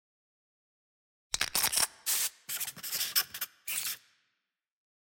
sfx-jfe-ui-logo.ogg